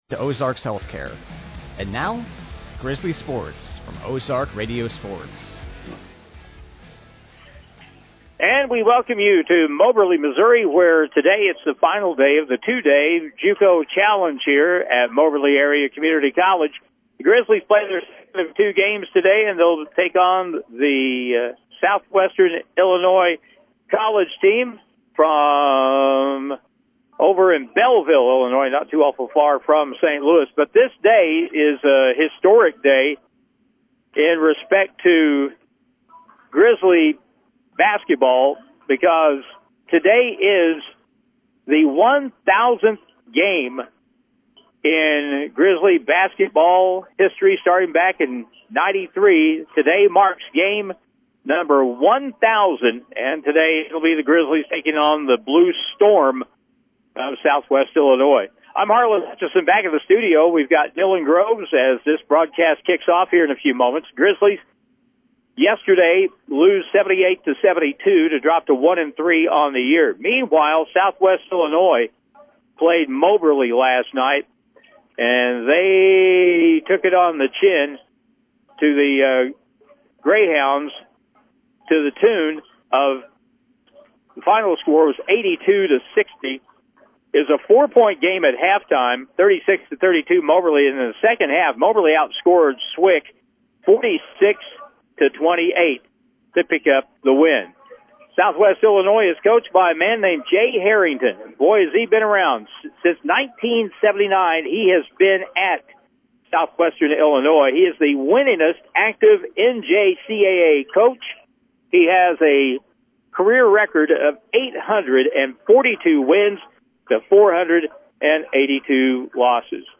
The Challenge hosted by Moberly Area Community College and played on the court of the Fitzsimmons-John Arena. Grizzlies were able to defeat the Southwestern, IL Blue Storm Kashmirs by a Score of 68-61.